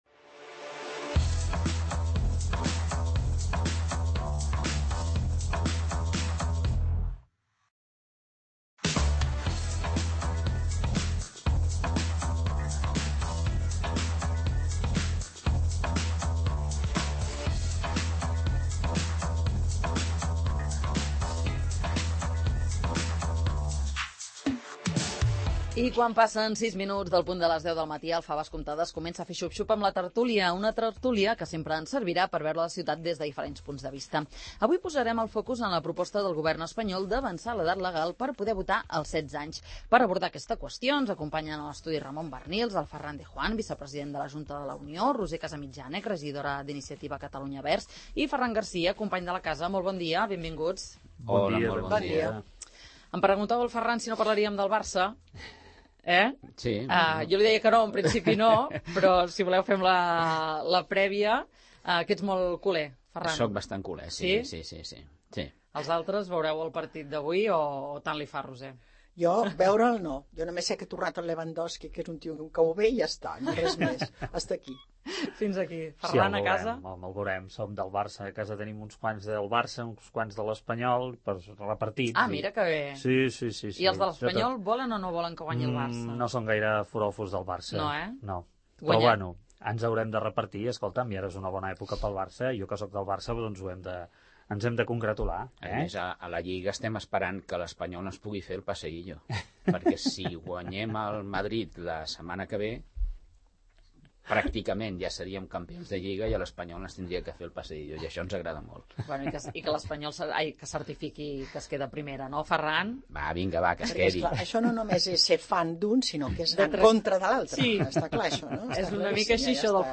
Els joves a partir de 16 anys han de poder votar? En parlem a la tert�lia del 'Faves comptades'